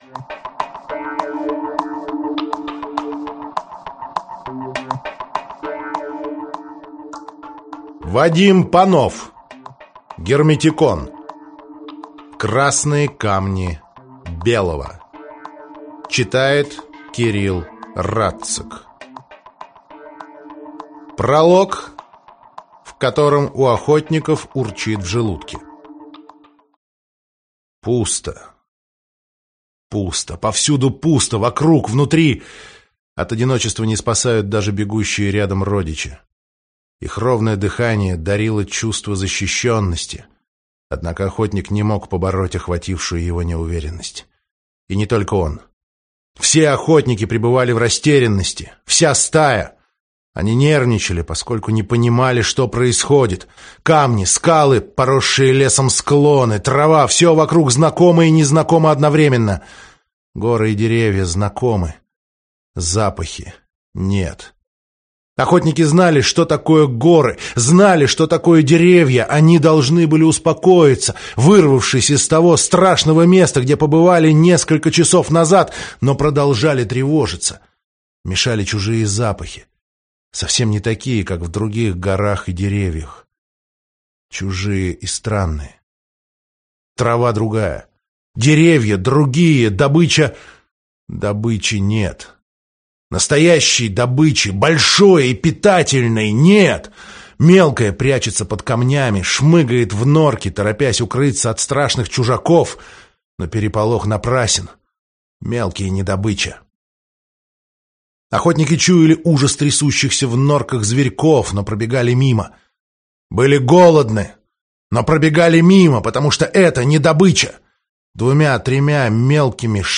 Аудиокнига «Красные камни Белого» в интернет-магазине КнигоПоиск ✅ в аудиоформате ✅ Скачать Красные камни Белого в mp3 или слушать онлайн